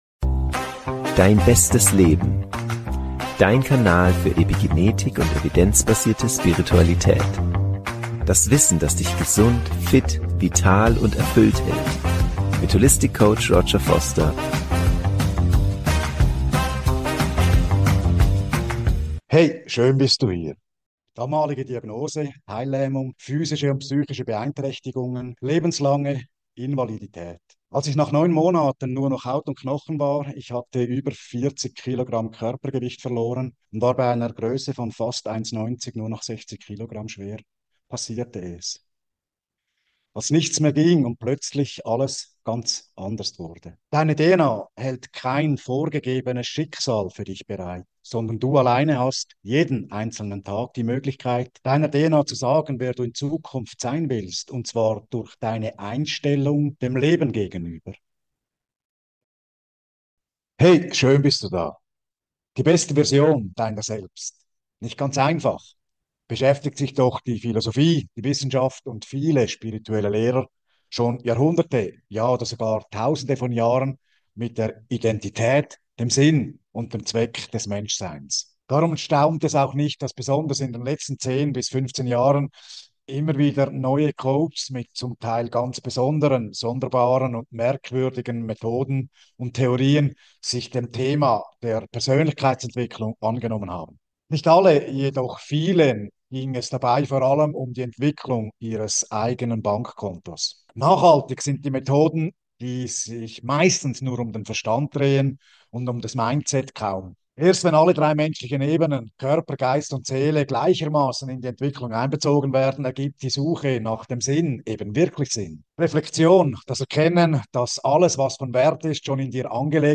Das Video stammt aus einem Webinar zu den 64 Genschlüsseln